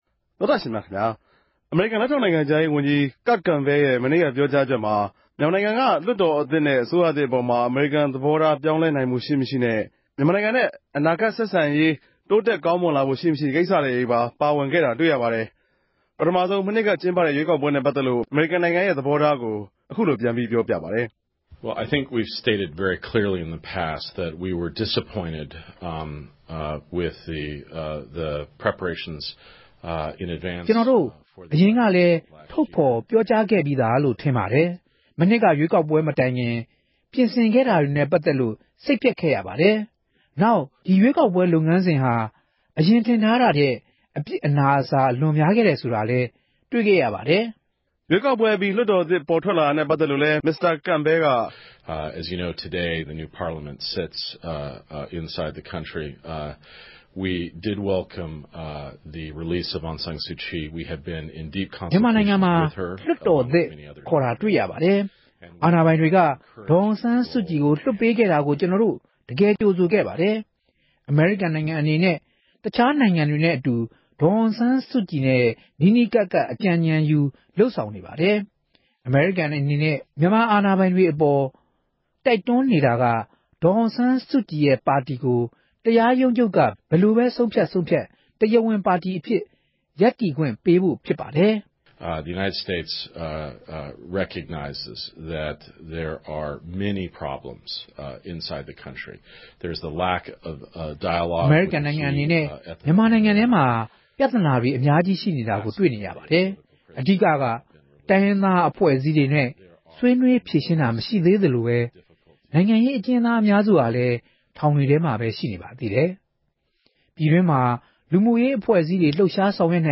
စုစည်းတင်ပြချက်